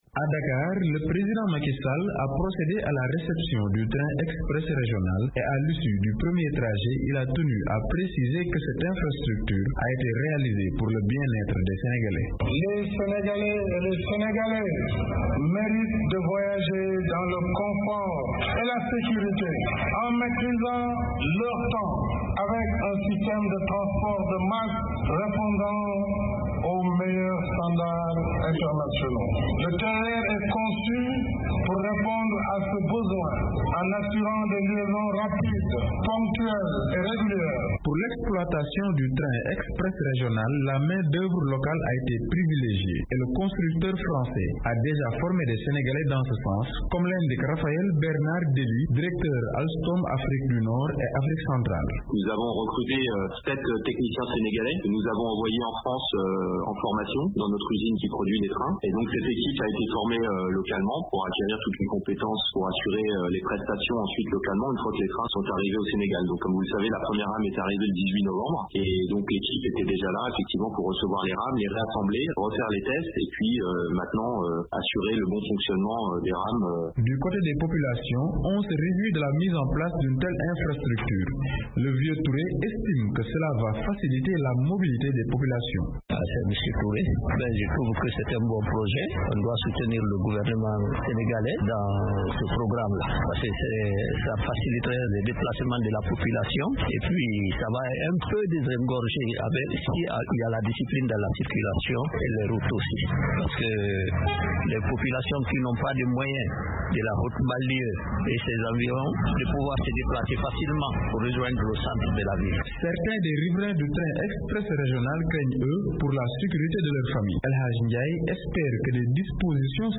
Le TER va répondre aux besoins des déplacements d'environ 124.000 passagers et il va générer 6000 emplois directs et indirects. De Dakar